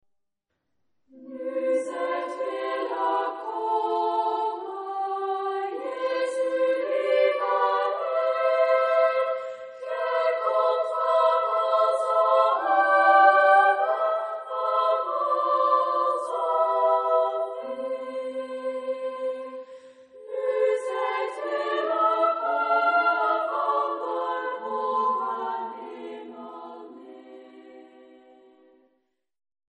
Genre-Style-Forme : Profane ; Sacré ; Chant de Noël
Caractère de la pièce : simple ; modéré ; sincère
Tonalité : do majeur